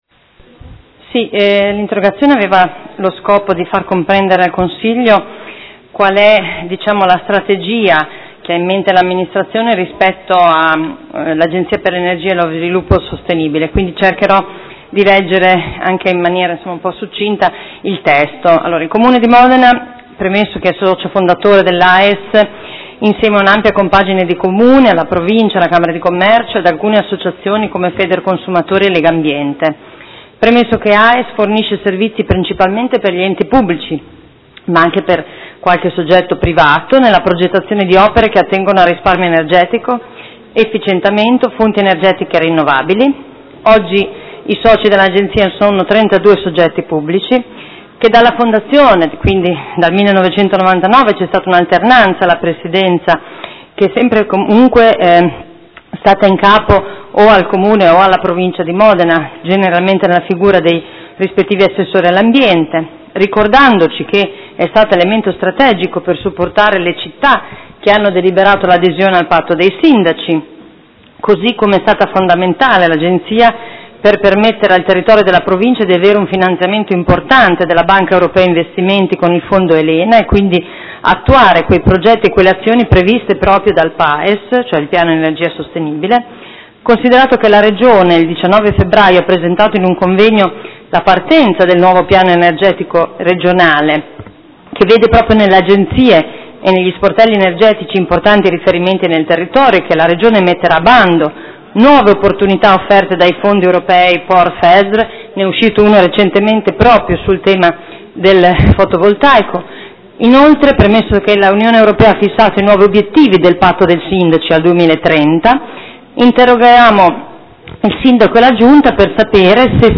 Seduta del 5/5/2016 Interrogazione della Consigliera Arletti (P.D.) avente per oggetto: Agenzia per l’energia e lo sviluppo sostenibile – quale futuro si sta costruendo?